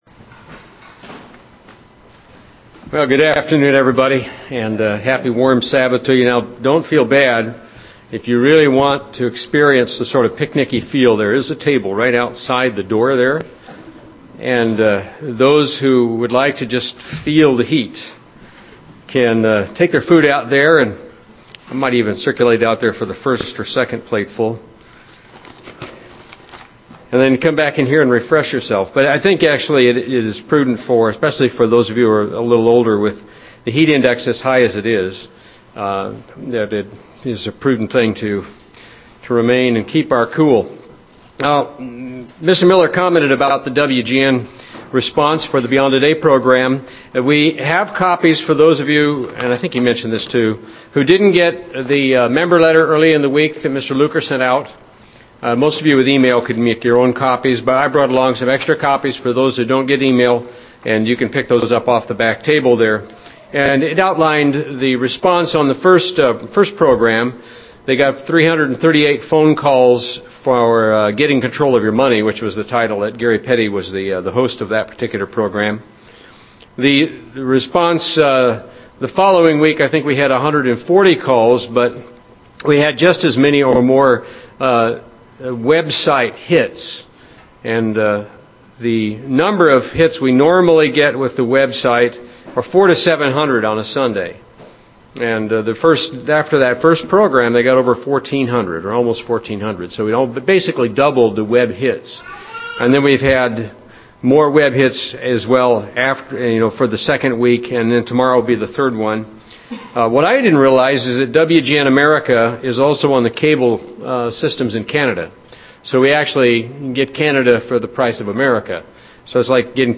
If so, how? 07/04/2010 UCG Sermon Studying the bible?